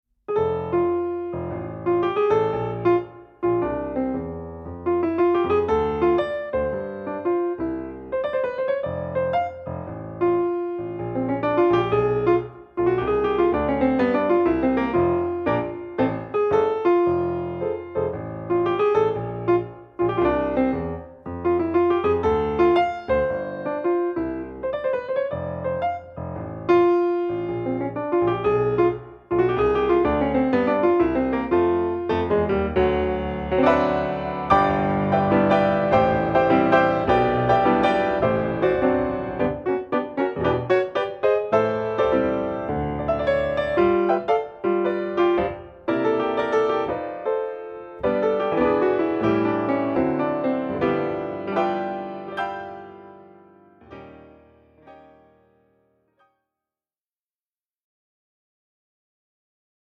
Recueil pour Piano